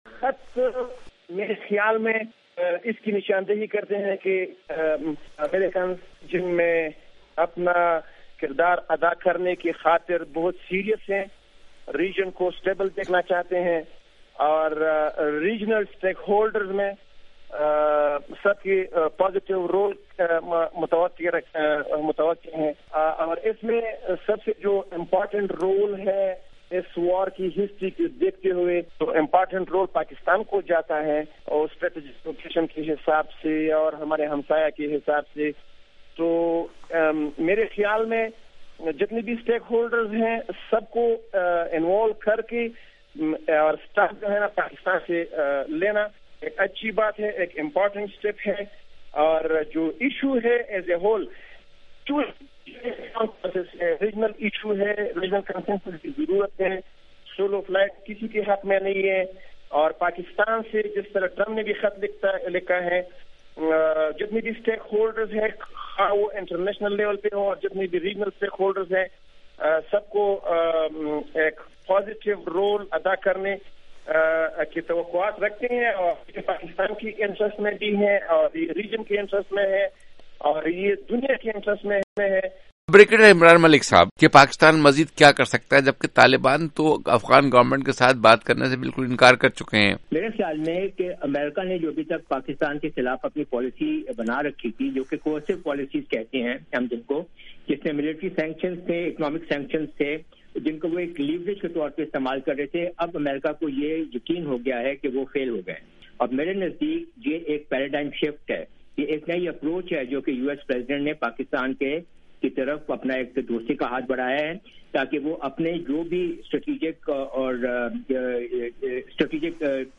دو ماہرین